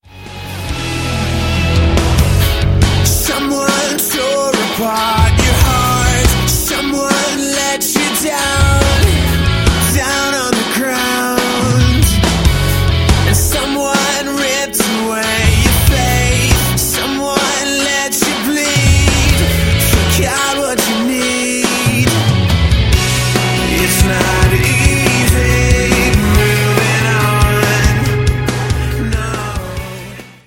• Sachgebiet: Rock